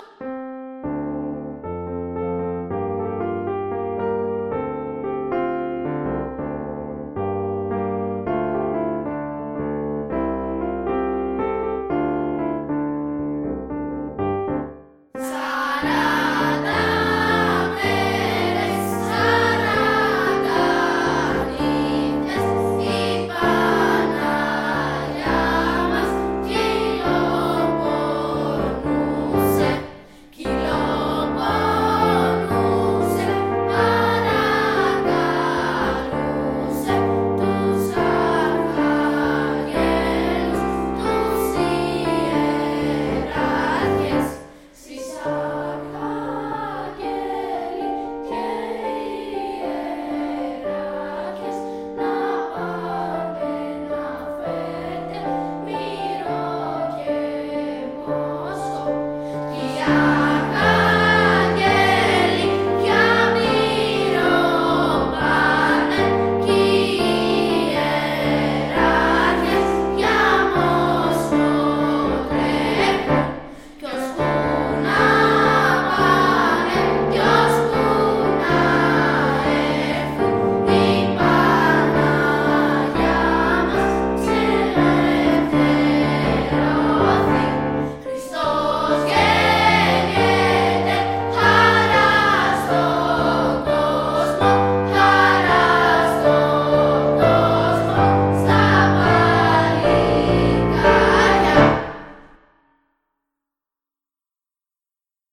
Ηχογραφήθηκαν το Δεκέμβριο του 2013 για συμμετοχή σε e-twinning project για παραδοσιακή μουσική.
Η ηχογράφηση έγινε με μικρόφωνο Behringer B-1, σε Linux με κάρτα ήχου Tascam US-122.